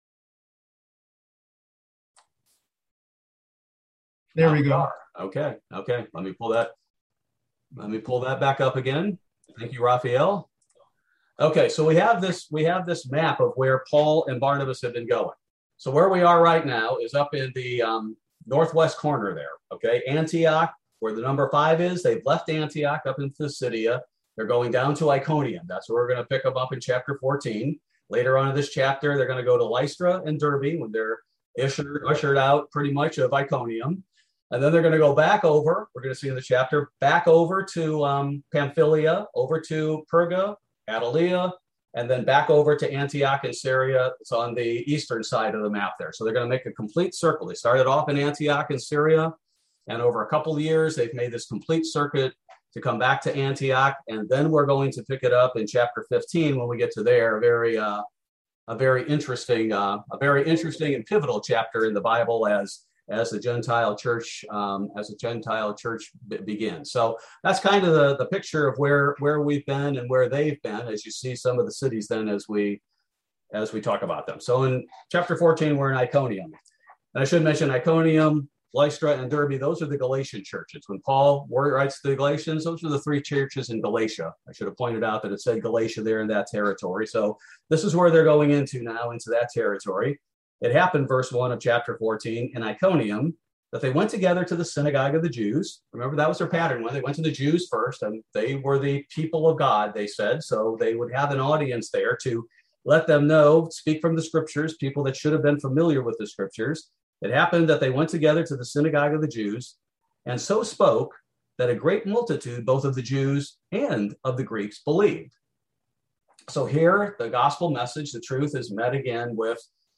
Bible Study: September 1, 2021